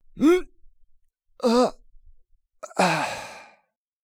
XS死亡2.wav
XS死亡2.wav 0:00.00 0:04.04 XS死亡2.wav WAV · 348 KB · 單聲道 (1ch) 下载文件 本站所有音效均采用 CC0 授权 ，可免费用于商业与个人项目，无需署名。
人声采集素材